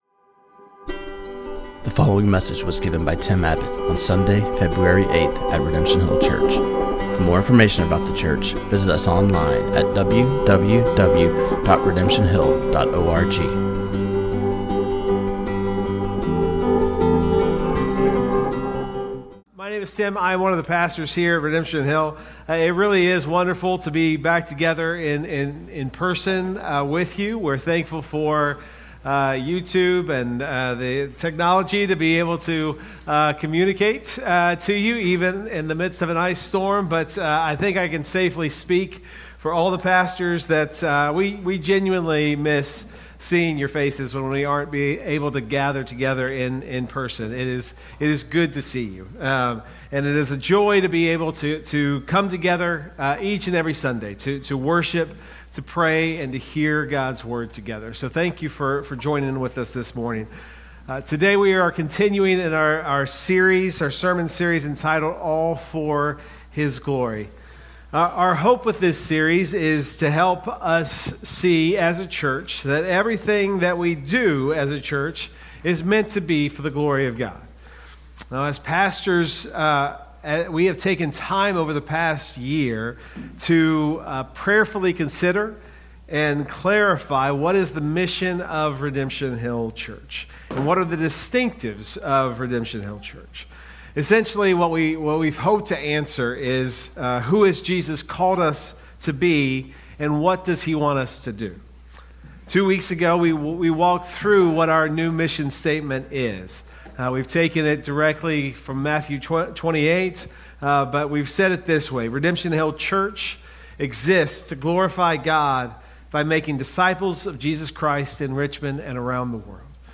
This sermon